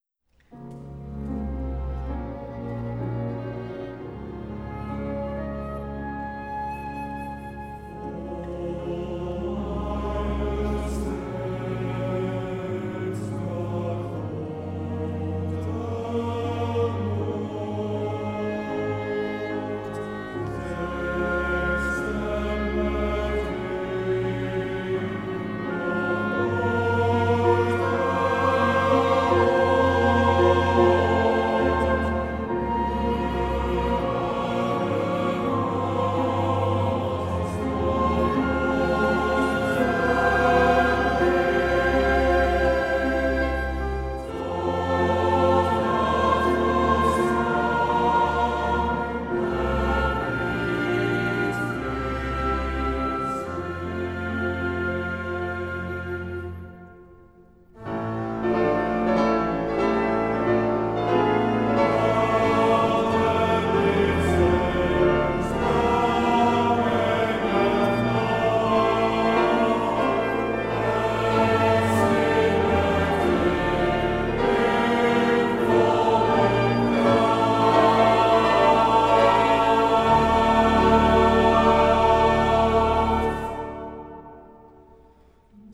CANTATE